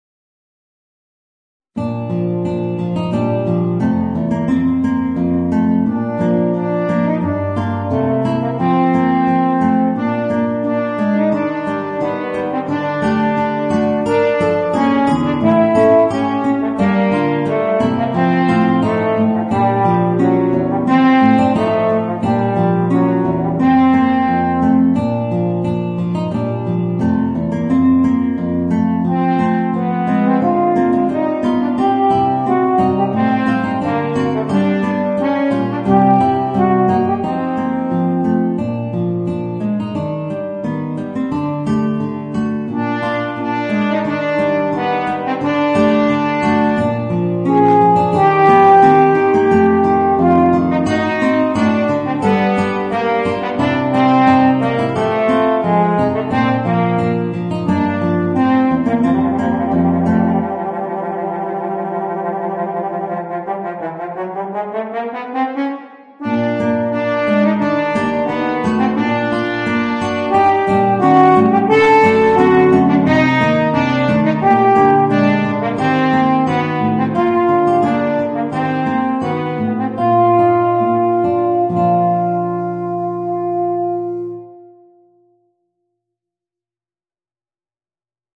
Voicing: Guitar and Eb Horn